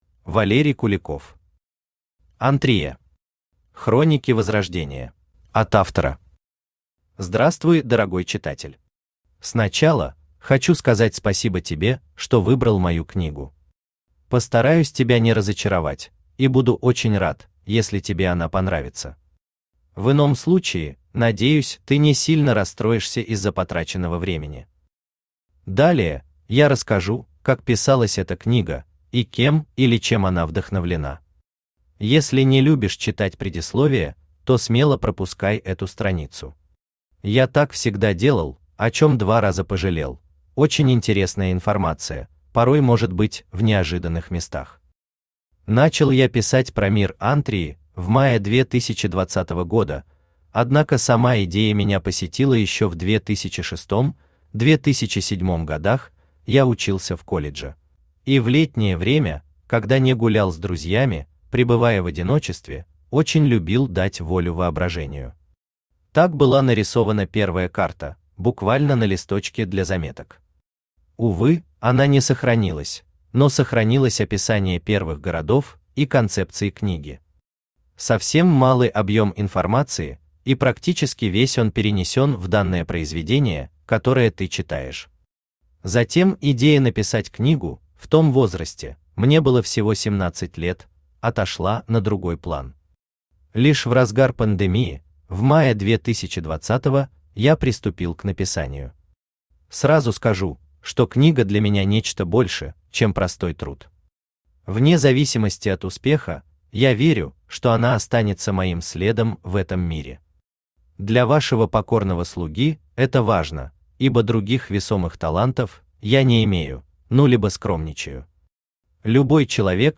Аудиокнига Антрия. Хроники возрождения | Библиотека аудиокниг
Хроники возрождения Автор Валерий Куликов Читает аудиокнигу Авточтец ЛитРес.